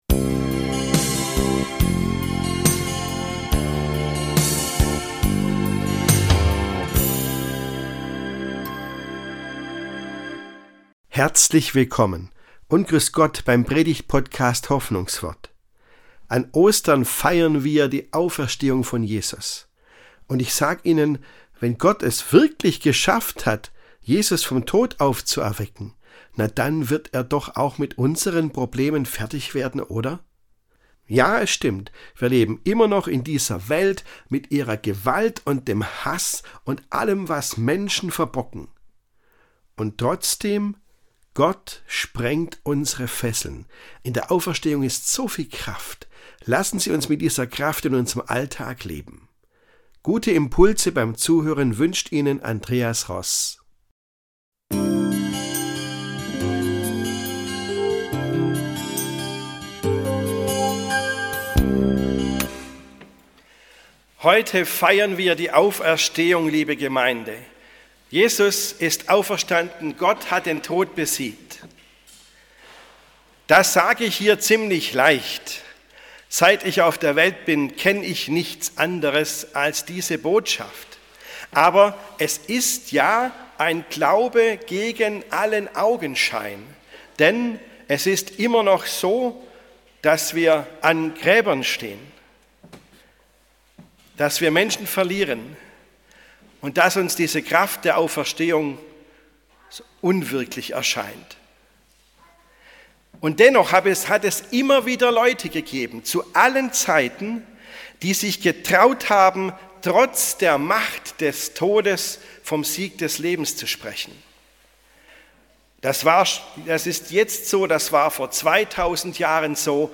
Ostern: Gott sprengt unsere Fesseln ~ Hoffnungswort - Predigten